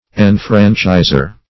Enfranchiser \En*fran"chis*er\, n. One who enfranchises.